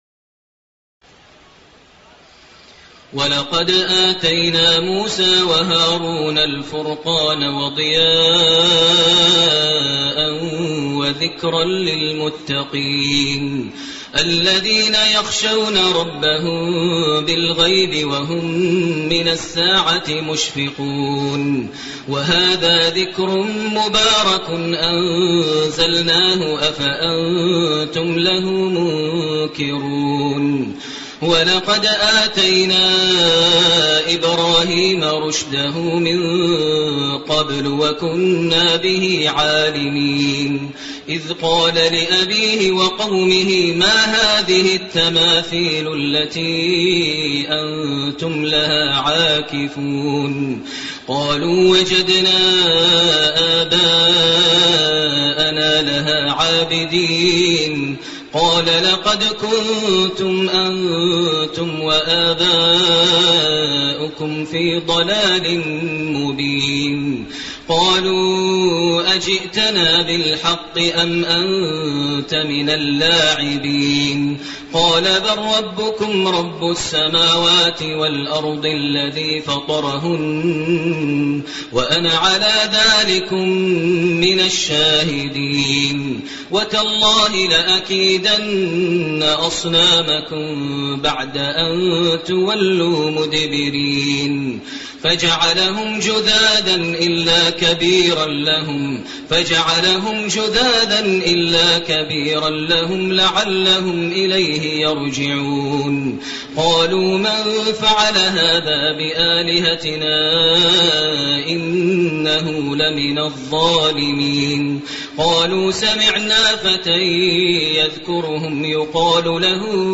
تراويح الليلة السابعة عشر رمضان 1429هـ سورة الأنبياء (48-112) Taraweeh 17 st night Ramadan 1429H from Surah Al-Anbiyaa > تراويح الحرم المكي عام 1429 🕋 > التراويح - تلاوات الحرمين